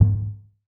Click (16).wav